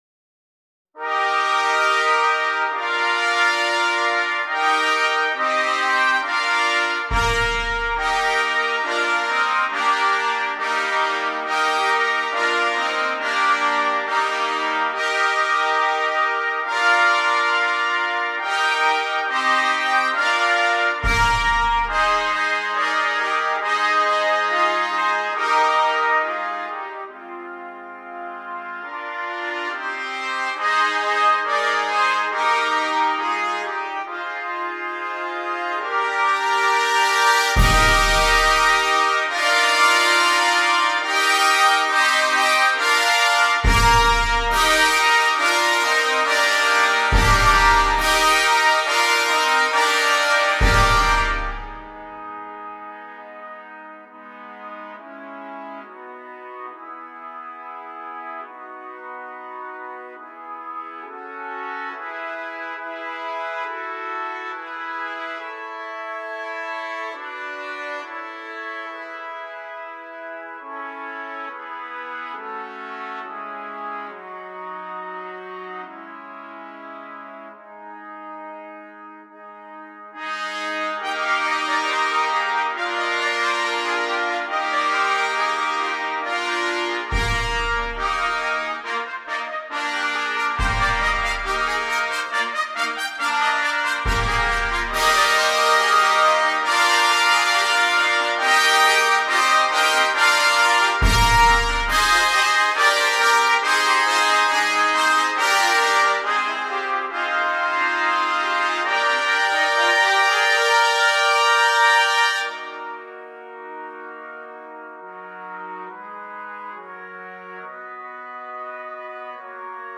13 Trumpets and Percussion